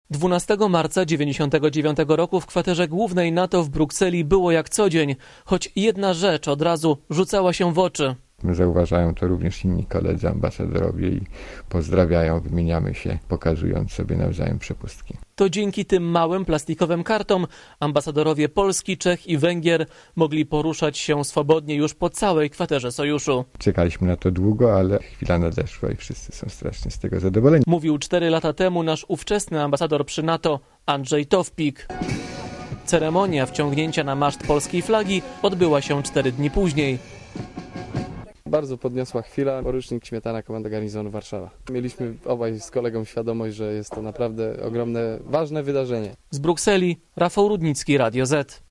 Posłuchaj co na to żołnierze (380Kb)